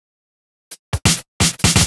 Fill 128 BPM (35).wav